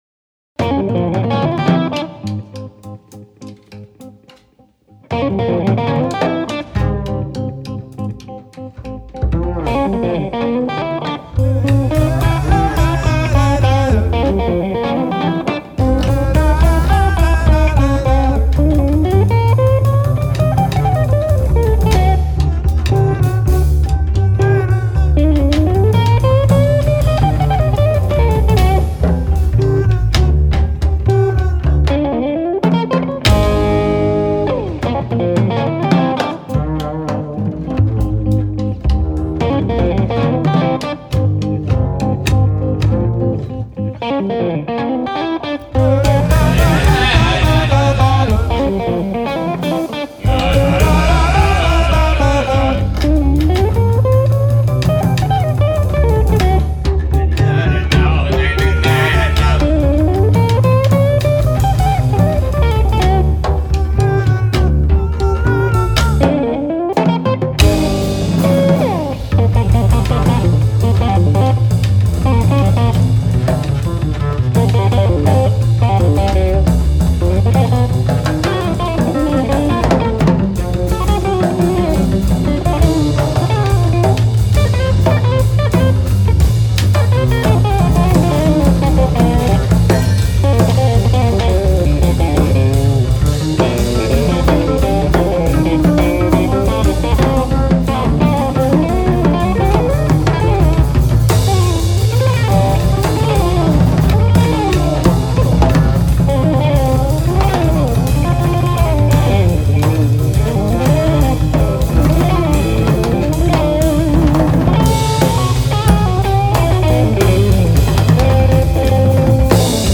batteria e percussioni
Jazz_core con nuances balkan_funk.